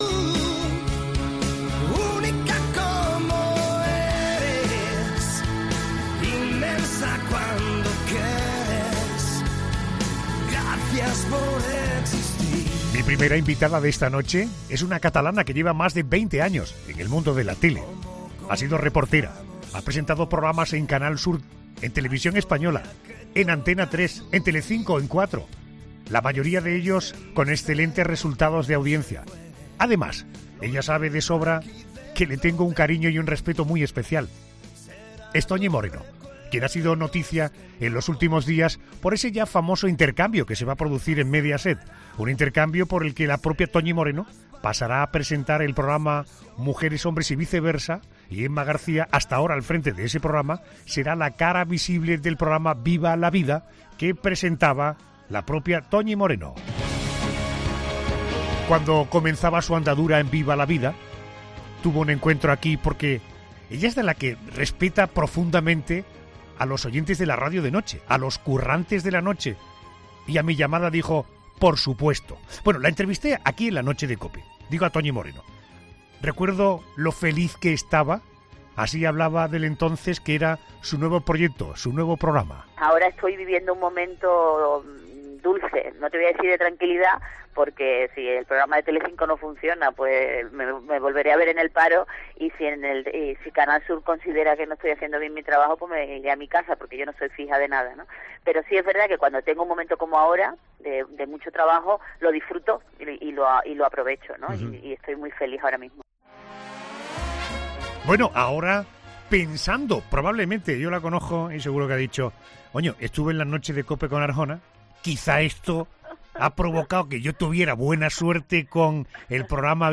Cuando comenzaba su andadura en ‘Viva la vida’ , pasó por ‘La Noche de COPE’, para mostrar su felicidad por comenzar un nuevo proyecto.